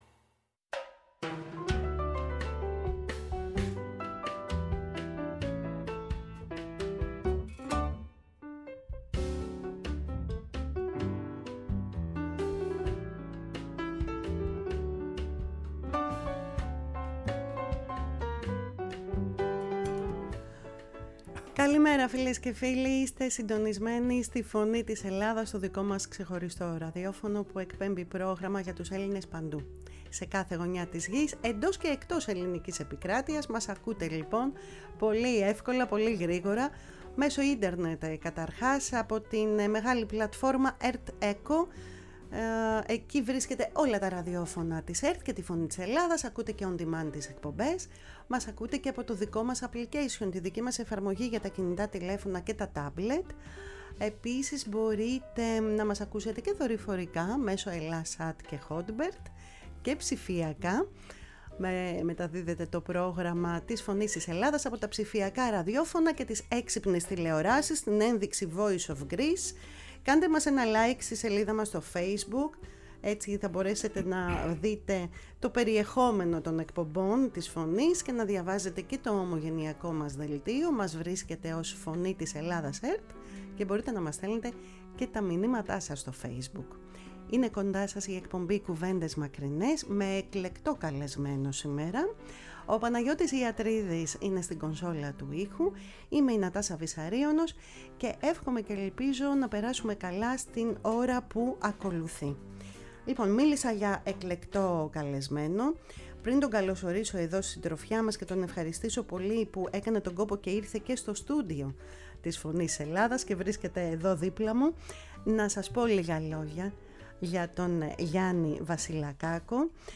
Στο στούντιο της “Φωνής της Ελλάδας” φιλοξενήθηκε από τη Μελβούρνη